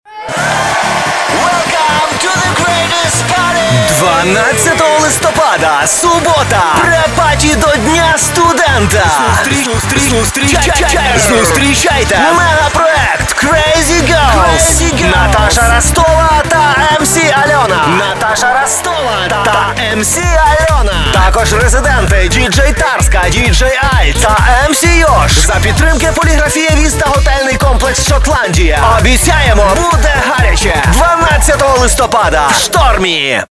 Радио-ролик для ночного клуба Шторм (укр.язык) Категория: Аудио/видео монтаж